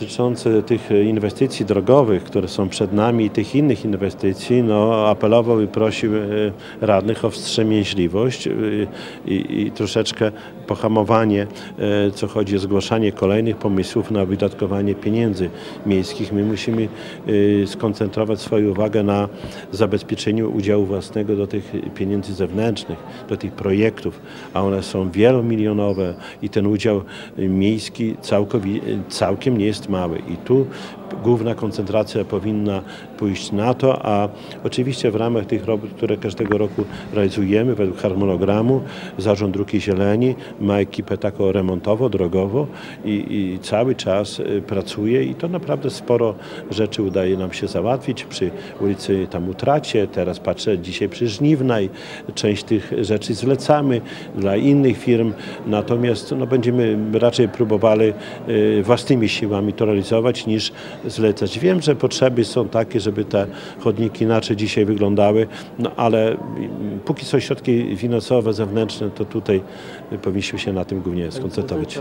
Czesław-Renkiewicz-prezydent-Suwałk-o-inwestycjach.mp3